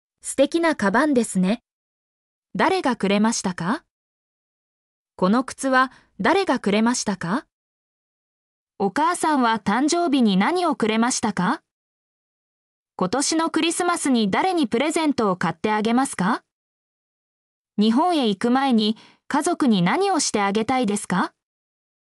mp3-output-ttsfreedotcom-63_gXADNTI9.mp3